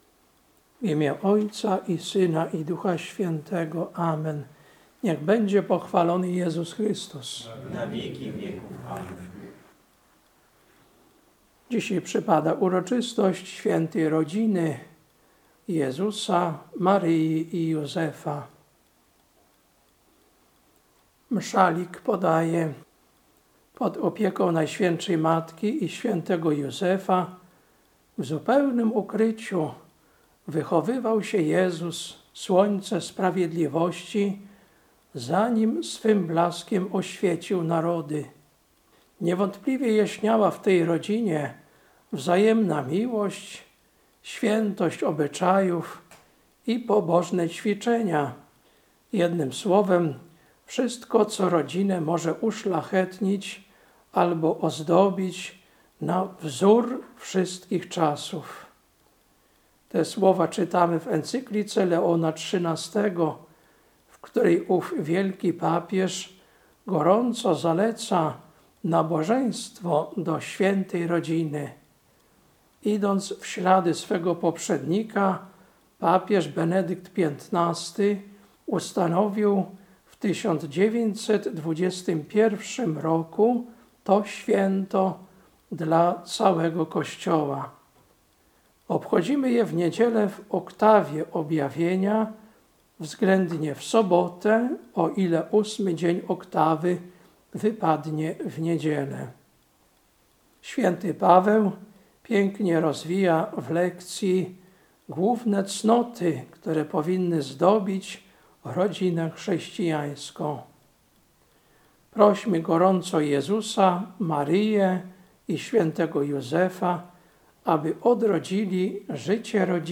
Kazanie na uroczystość Świętej Rodziny Jezusa, Maryi i Józefa, 11.01.2026 Lekcja: Kol 3, 12-17 Ewangelia: Łk 2, 42-52